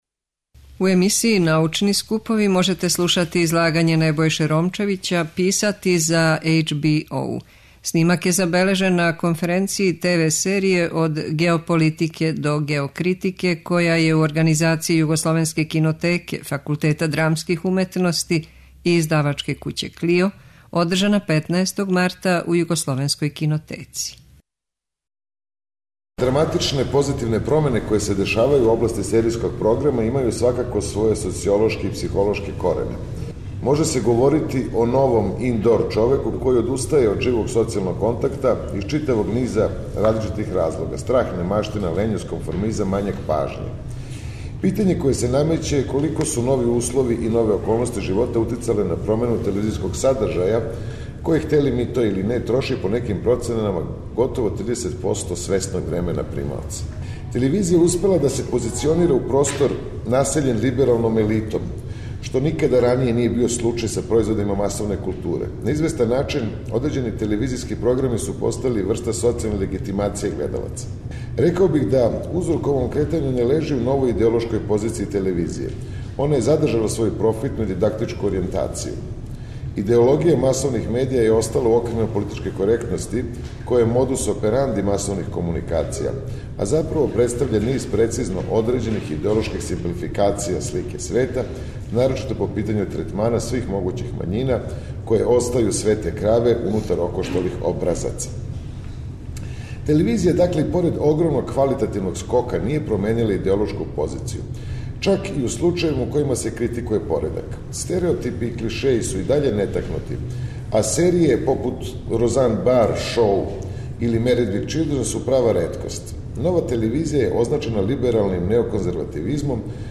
У емисији „Научни скупови”, у петак, 24. марта, можете слушати излагање Небојше Ромчевића „Писати за ХБО”.
преузми : 7.88 MB Трибине и Научни скупови Autor: Редакција Преносимо излагања са научних конференција и трибина.